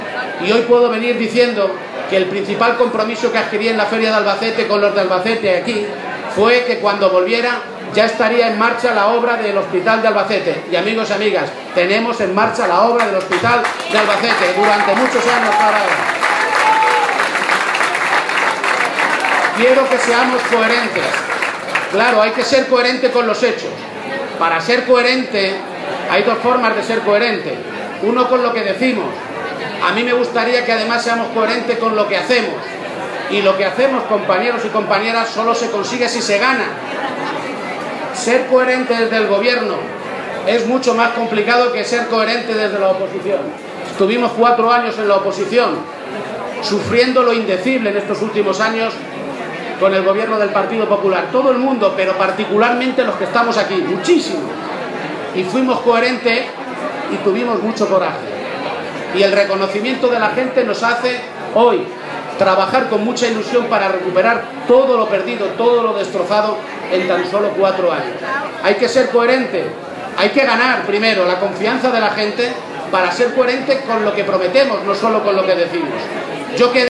García-Page en la entrega de los Premios "Pablo Iglesias" entregados en la caseta de la Casa del Pueblo, en el Recinto Ferial de Albacete
Cortes de audio de la rueda de prensa